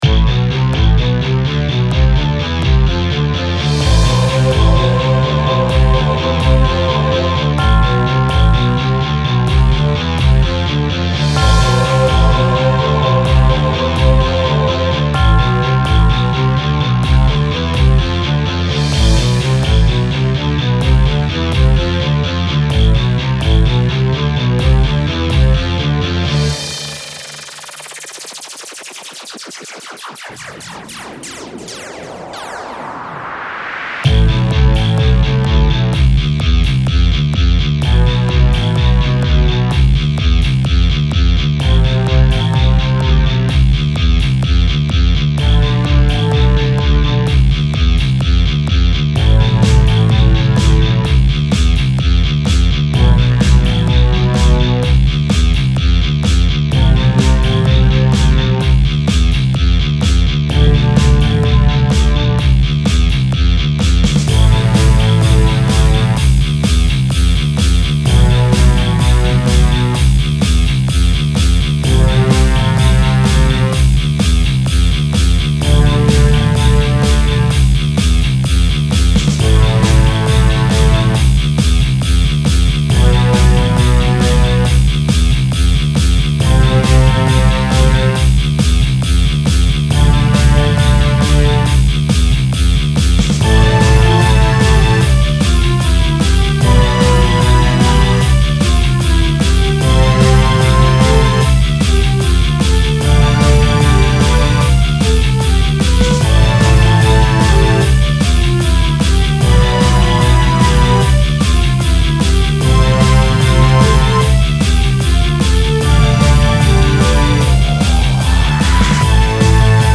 Depending on how long you have been on the site, you may remember a time where I attempted to compose rock music.
Heart of the Storm sounds much more balanced, melodically and flows better.  Love the change in BPM near the end, really gives it drive and a sense of urgency.
I think partially because it builds off of Edge of the Storm in using the same motiff and sounds a bit more... dramatic, or climatic, especially near the end.